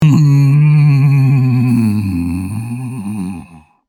Sound Effects
Zombie Moan Sfx